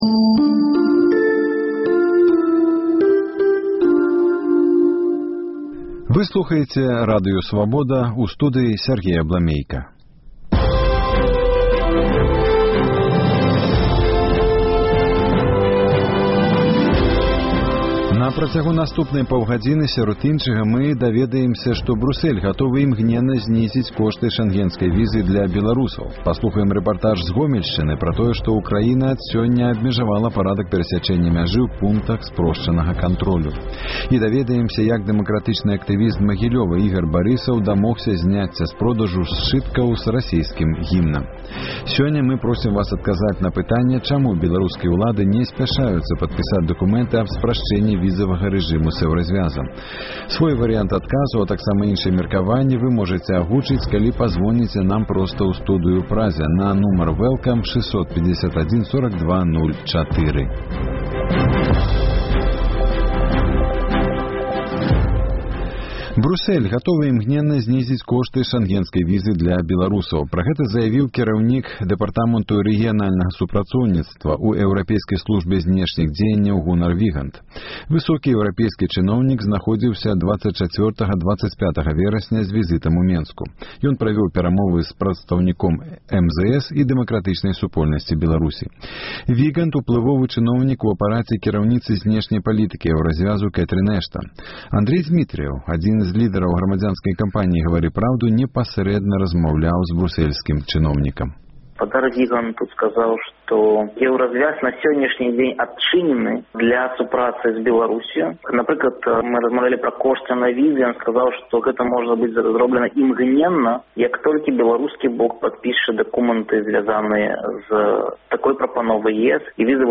Хто супраць палягчэньня візавага рэжыму з Эўразьвязам? Жывы эфір